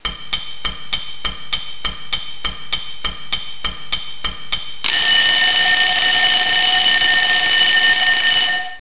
Clock.wav